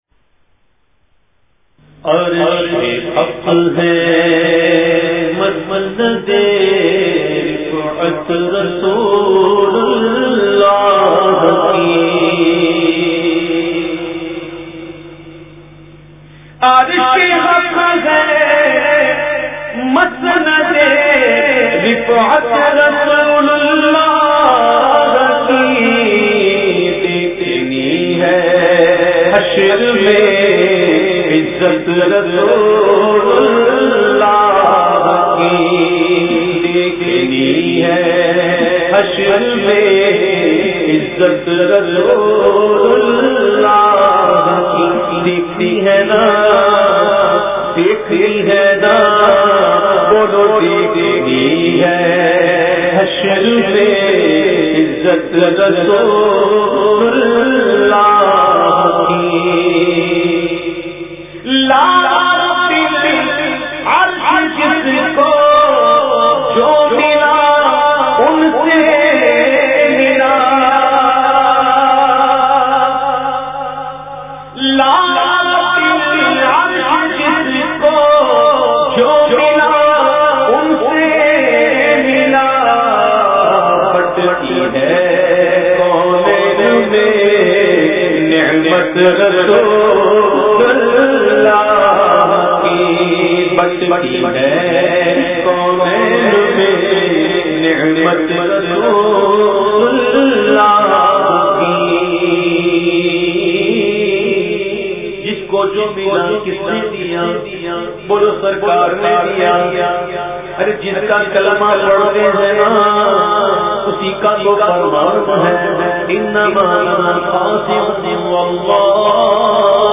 The Naat Sharif Arshe Haq Hai Masnade Rifat Rasoolullah Ki recited by famous Naat Khawan of Pakistan owaise qadri.